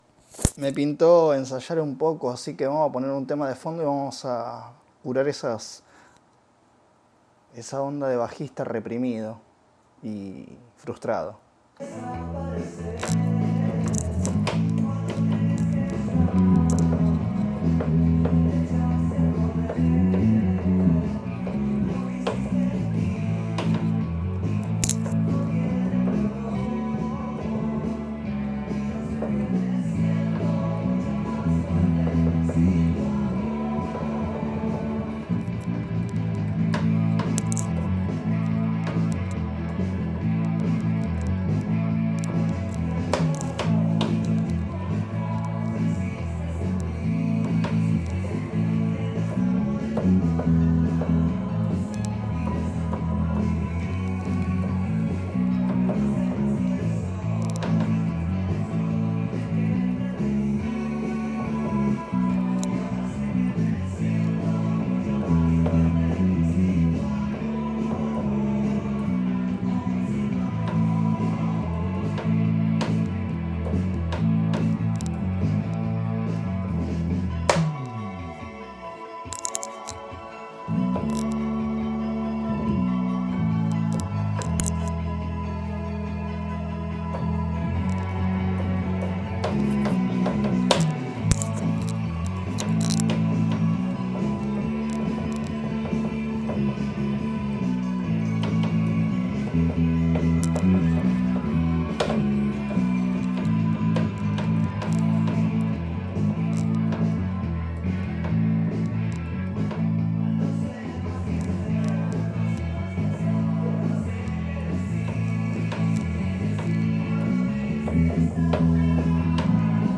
Ensayo con trilogy